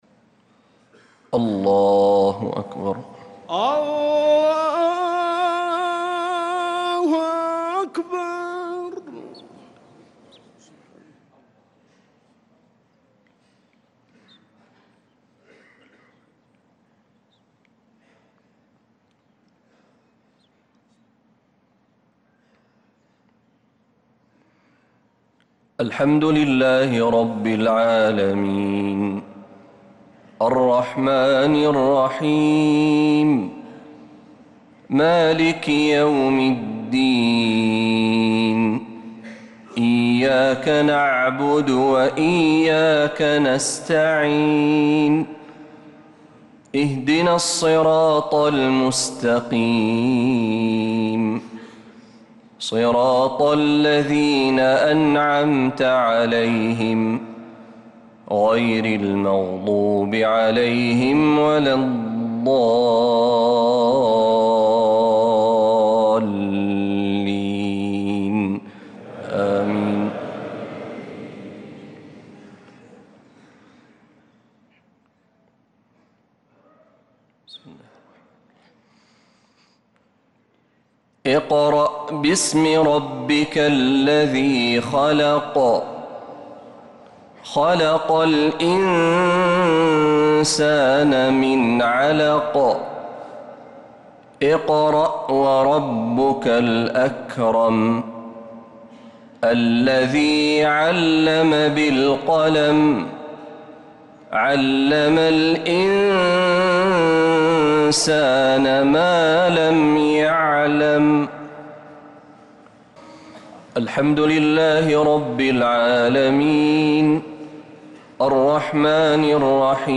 صلاة المغرب للقارئ محمد برهجي 24 ربيع الآخر 1446 هـ